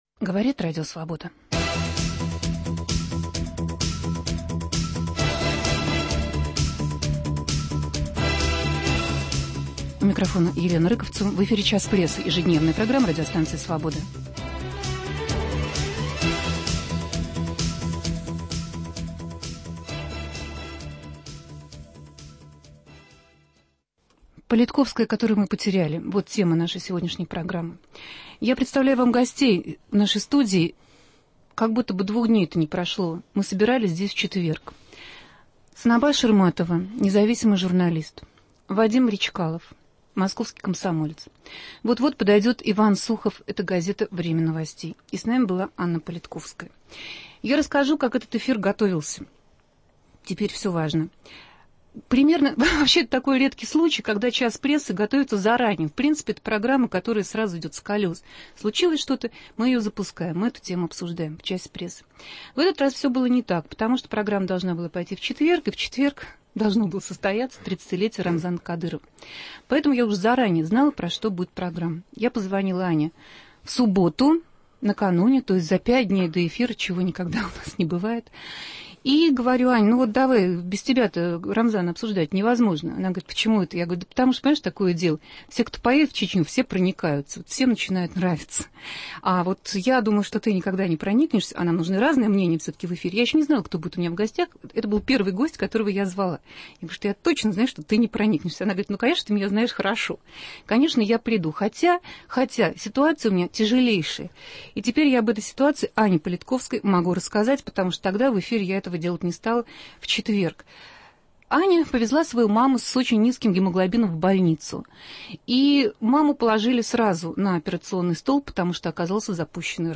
которую мы потеряли Гости студии